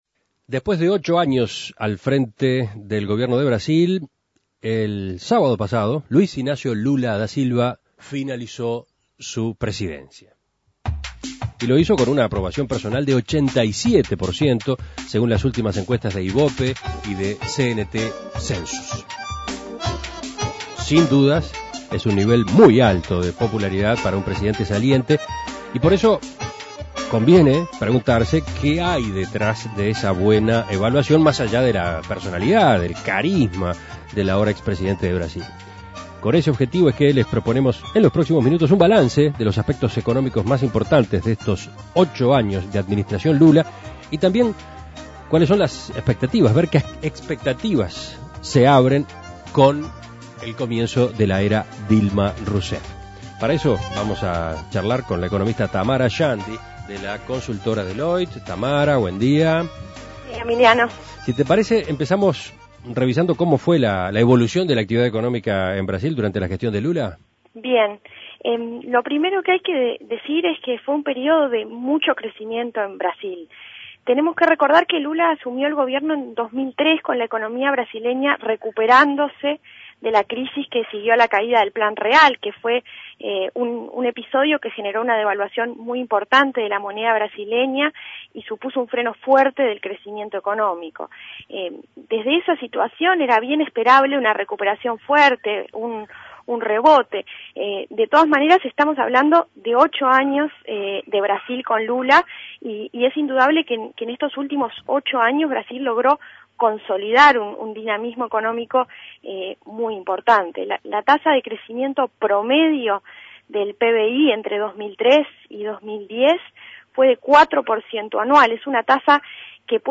Análisis Económico Lula dejó la presidencia de Brasil tras ocho años de Gobierno.